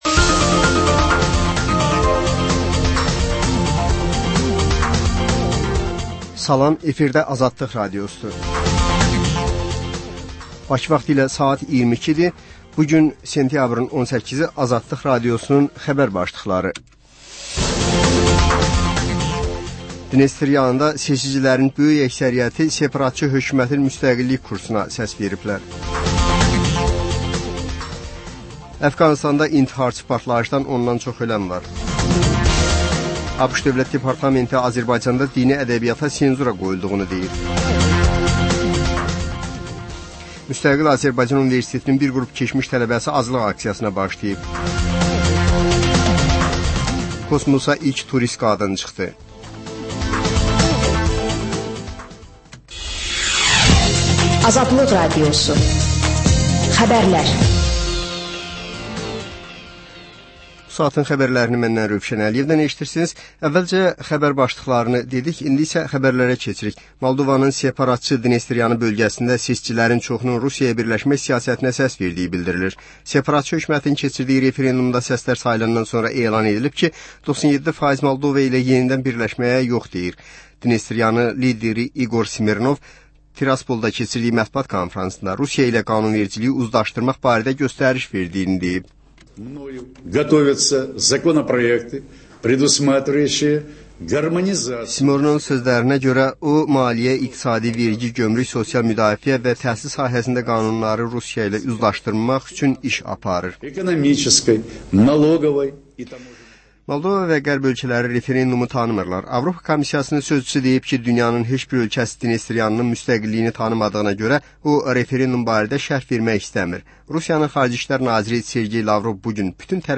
Xəbərlər, reportajlar, müsahibələr. Və sonda: Azərbaycan Şəkilləri: Rayonlardan reportajlar.